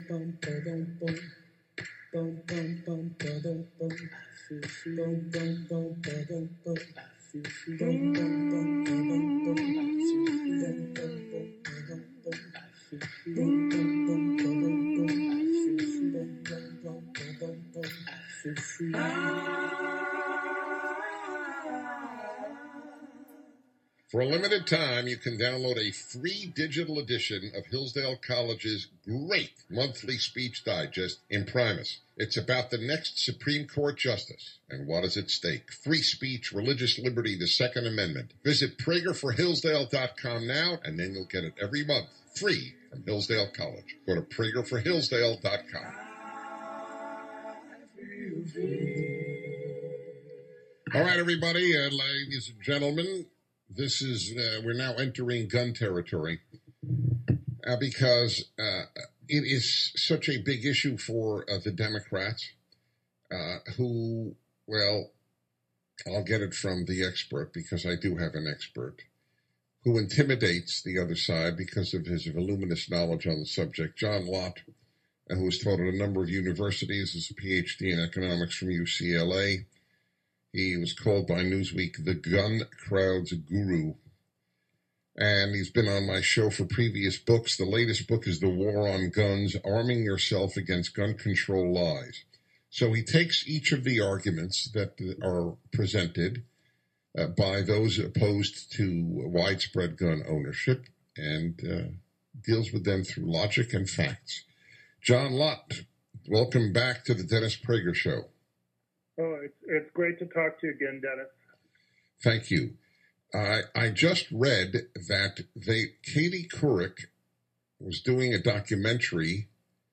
Dr. John Lott spoke with host Dennis Prager about his new book “The War on Guns” and how many gun control laws actually make law-abiding people more vulnerable and unable to defend themselves. Also discussed is the claim that Michael Bloomberg insisted that Dr. Lott’s taped interview be removed from the Couric gun-control film.